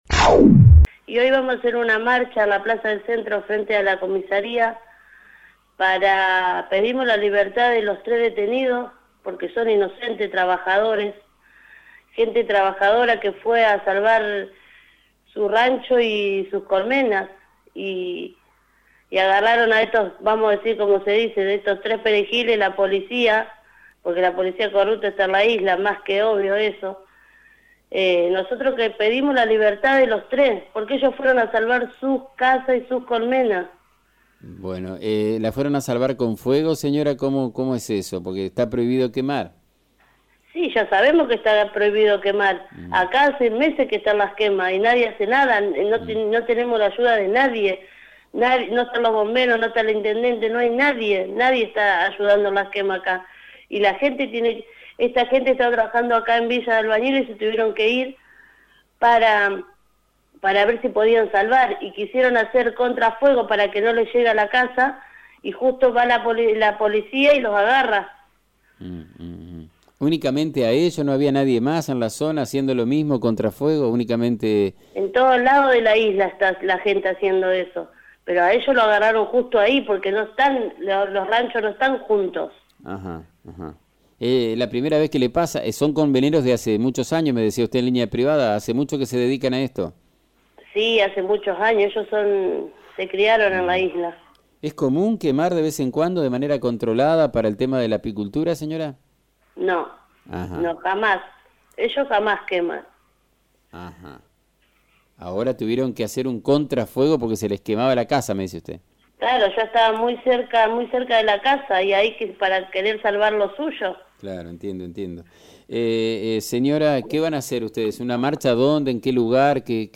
En diálogo con FM 90.3, una familiar de los detenidos declaró que “Agarraron a tres perejiles”.